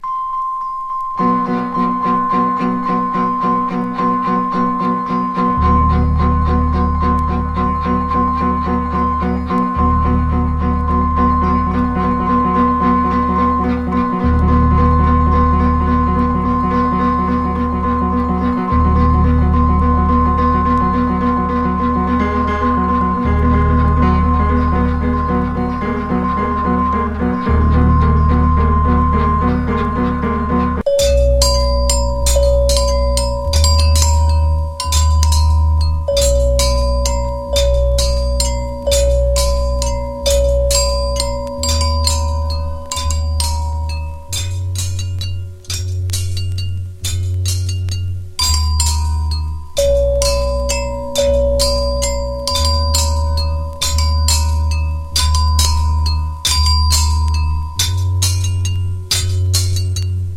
ミスタッチから着想を得たエラー・ミニマルミュージック